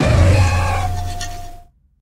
Cri de Serpente-Eau dans Pokémon HOME.